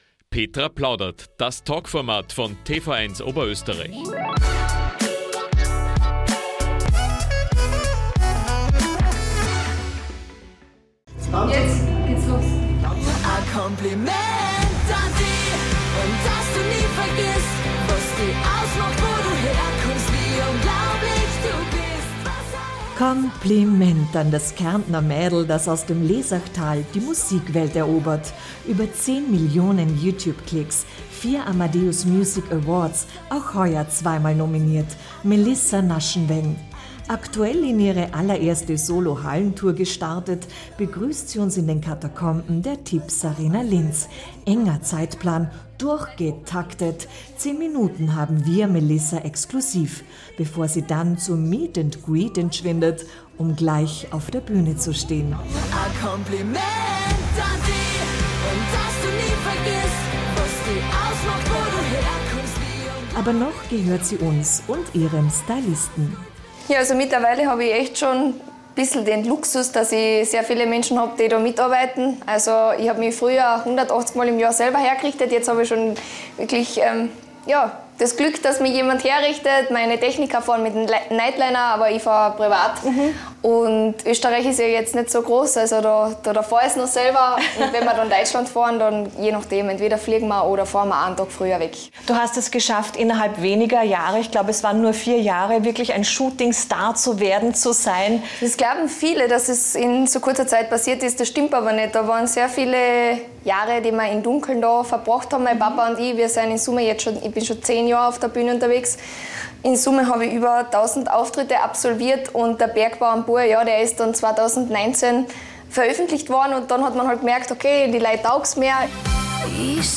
erfolgreichste Schlagersängerin Österreichs in ihrer Garderobe -